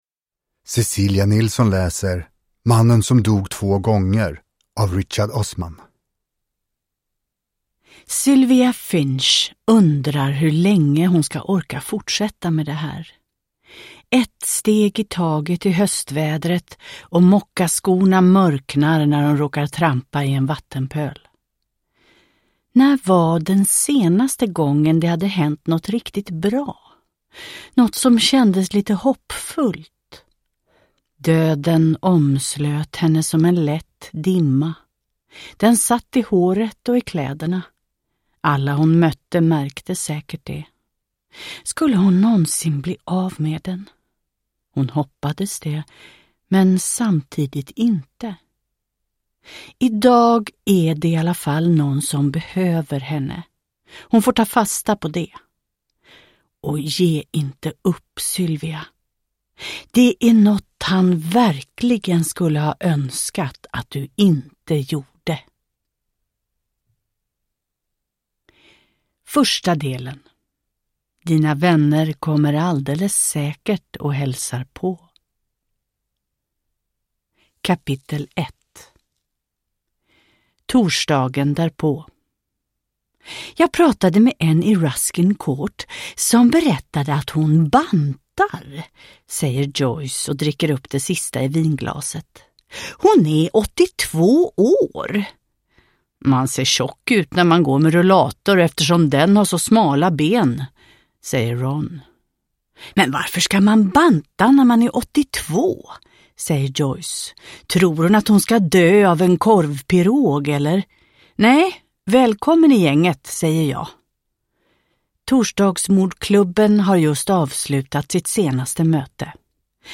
Mannen som dog två gånger – Ljudbok – Laddas ner
Uppläsare: Cecilia Nilsson